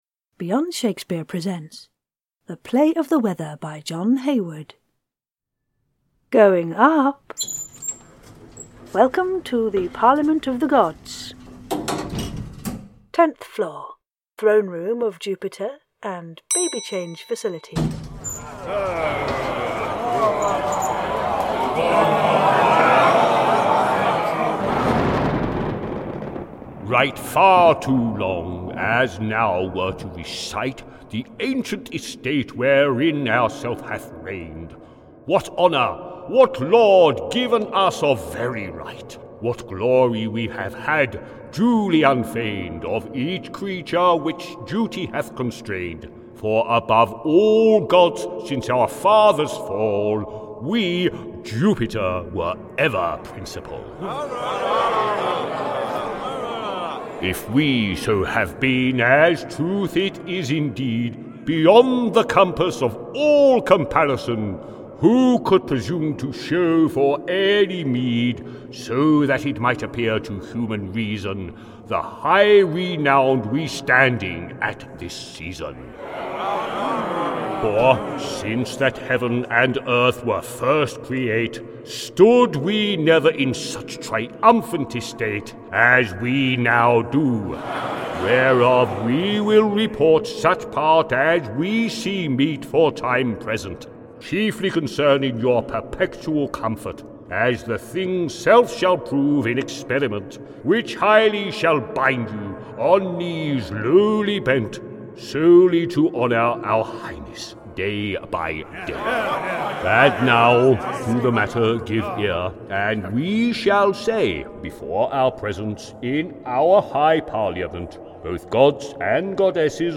A Full Cast Audio Adaptation